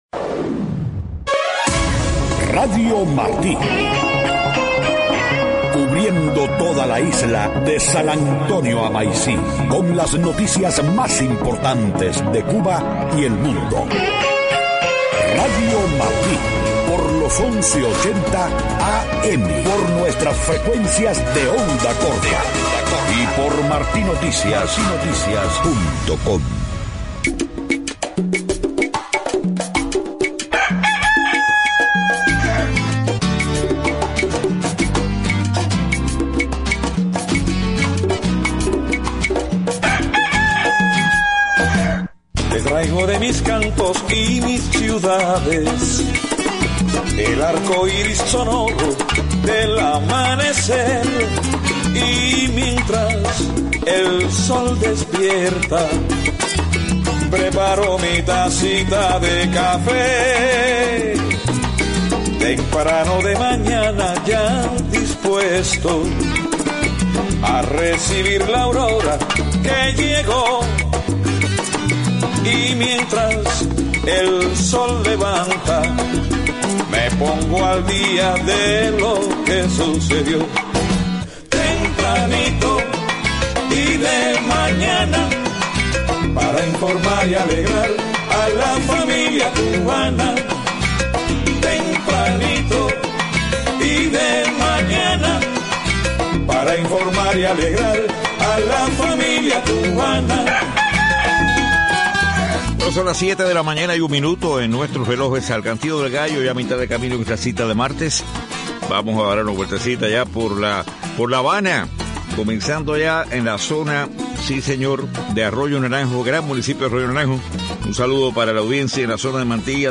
7:00 a.m Noticias: